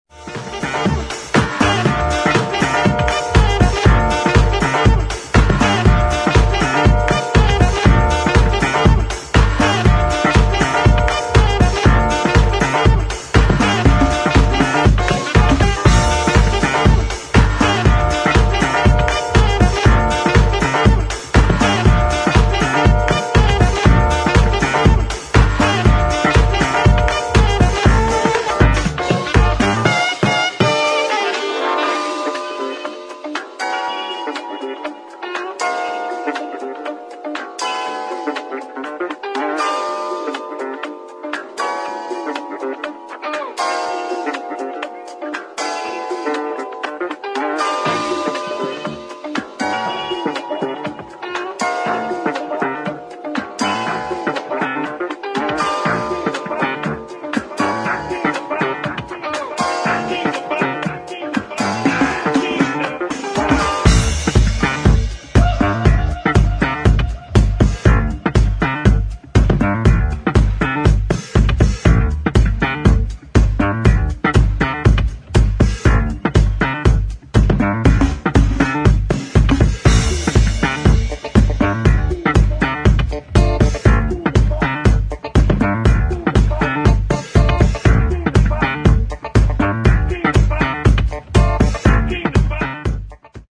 [ DISCO / EDIT ]
スラップ・ベース、華やかなサックスのピークタイム・デイスコ